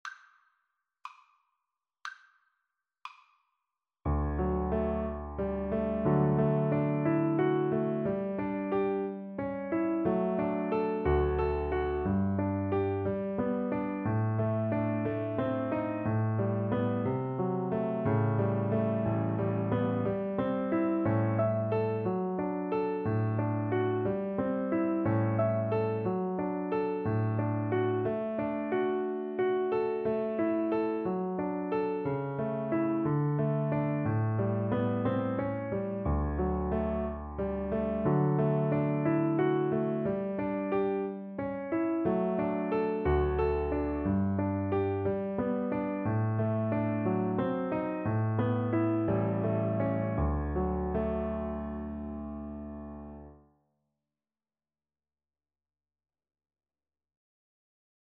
~ = 60 Andantino (View more music marked Andantino)
Classical (View more Classical Cello Music)